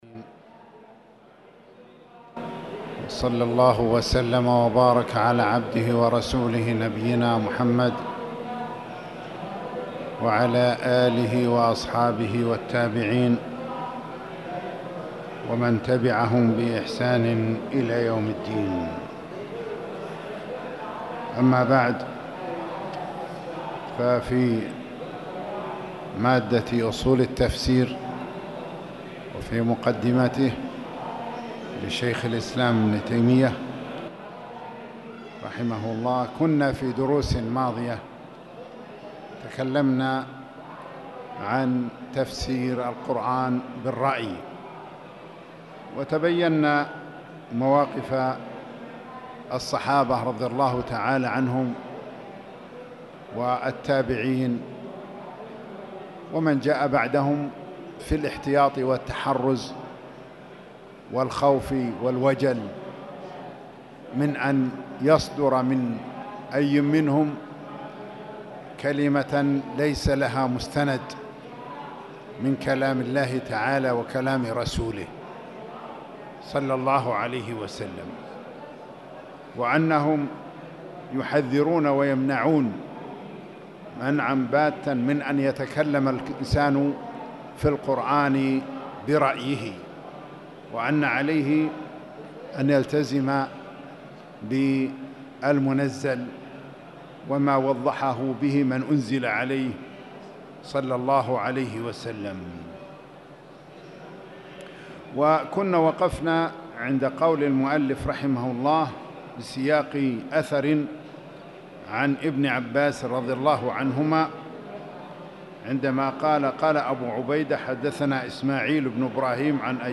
تاريخ النشر ٢٩ محرم ١٤٣٨ هـ المكان: المسجد الحرام الشيخ